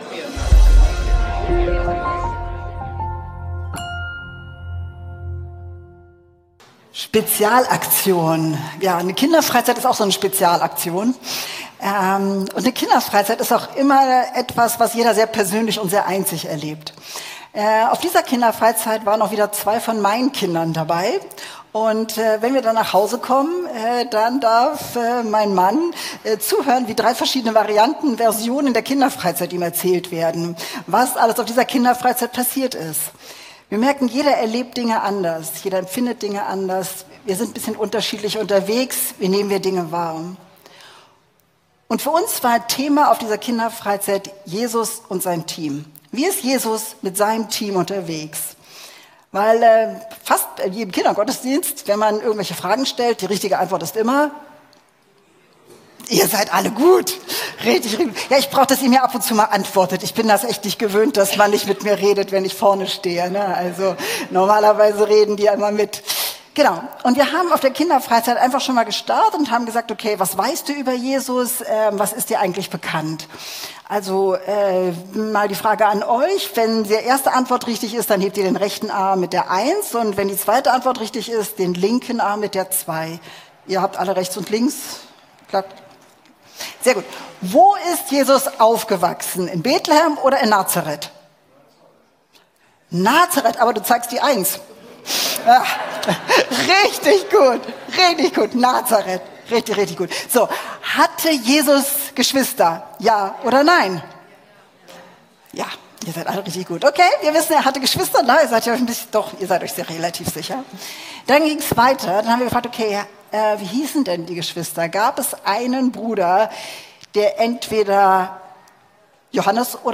Gottesdienst zur Kinderfreizeit ~ Predigten der LUKAS GEMEINDE Podcast